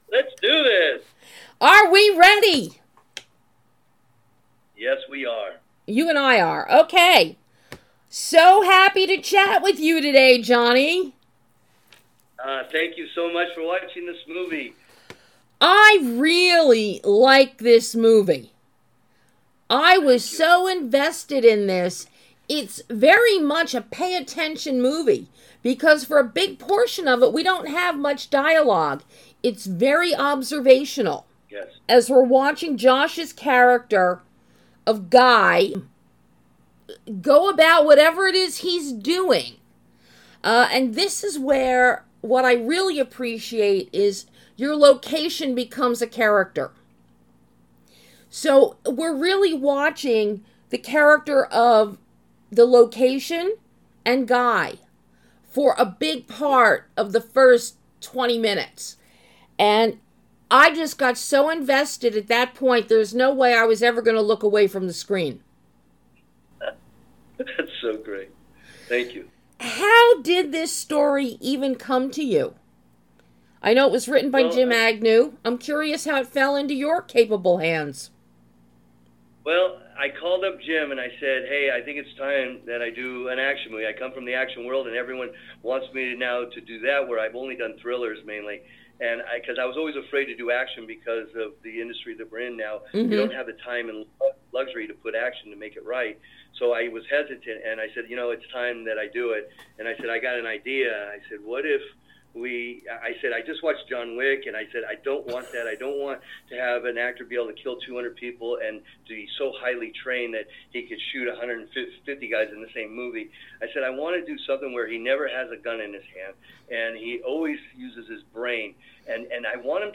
- Exclusive Interview